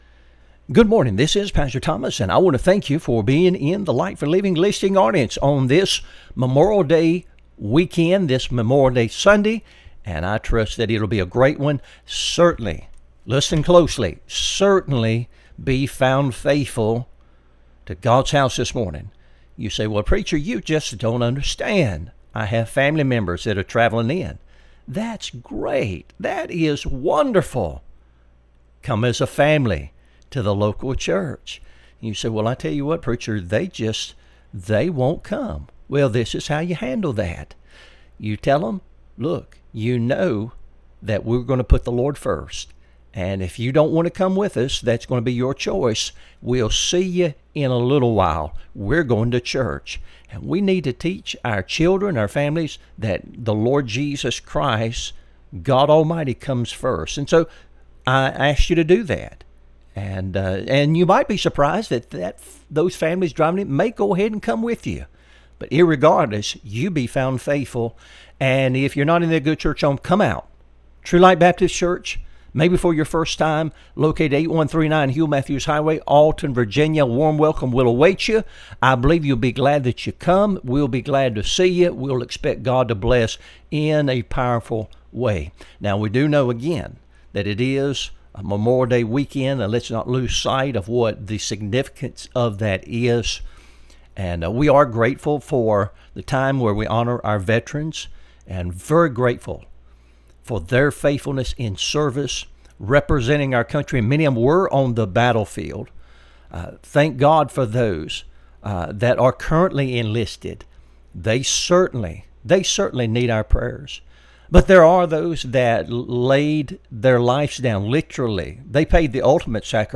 Sermons | True Light Baptist Church of Alton, Virginia